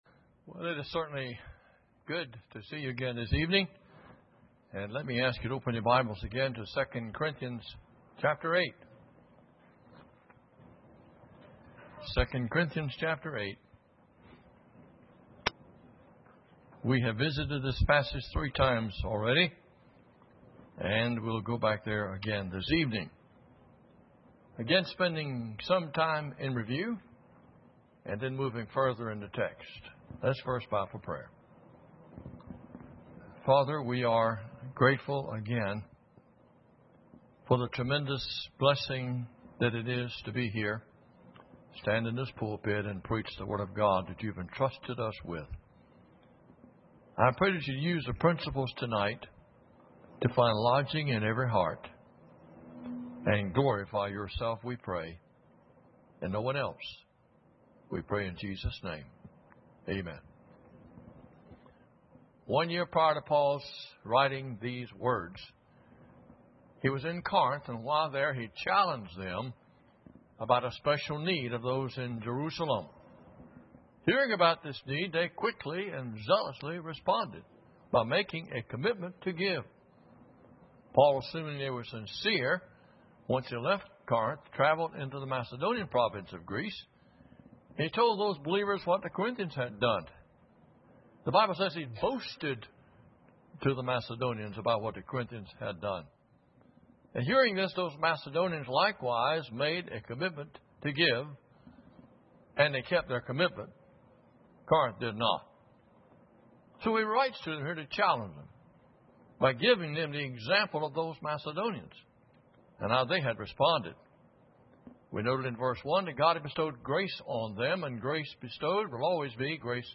Series: 2009 Missions Conference Service Type: Special Service